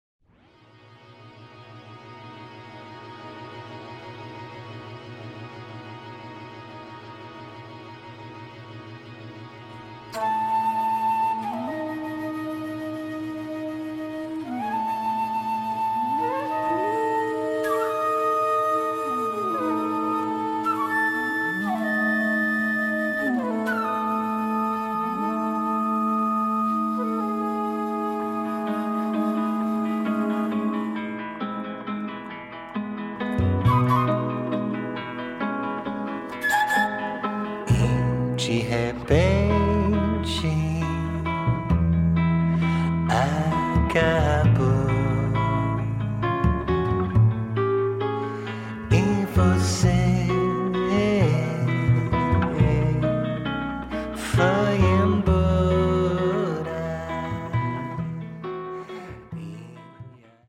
Brazilian psych vibes